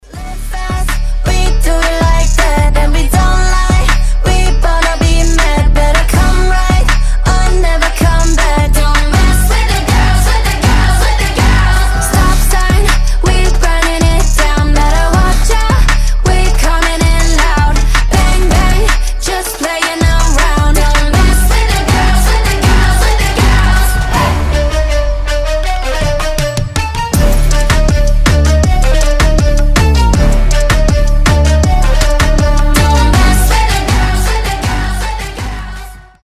поп , рэп
k-pop